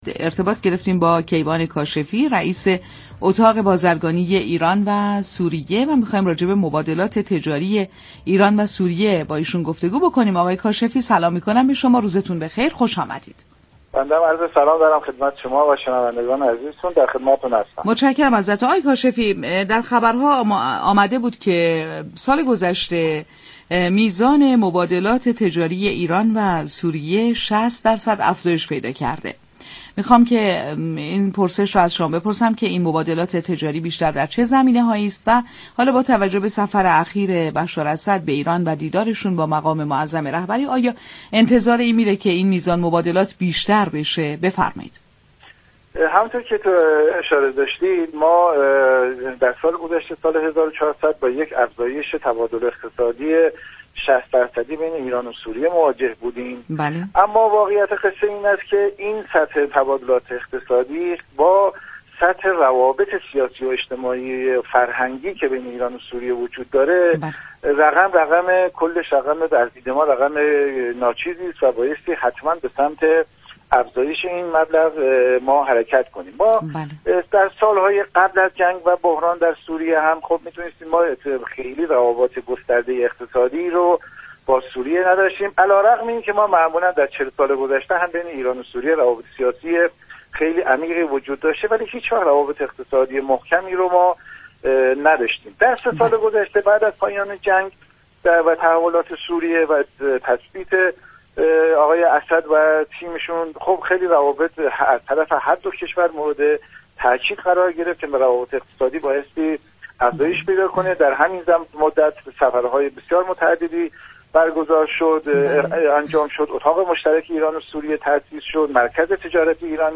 در گفتگو با بازار تهران رادیو تهران